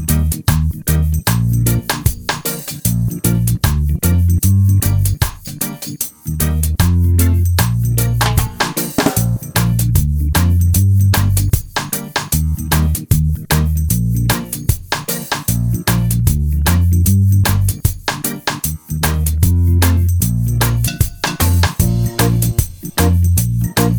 minus guitars no Backing Vocals Reggae 3:03 Buy £1.50